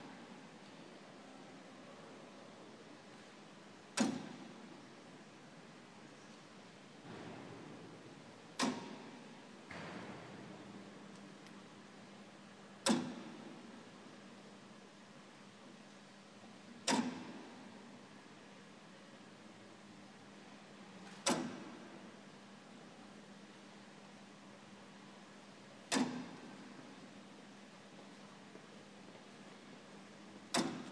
The Mediaeval Clock - Salisbury Cathedral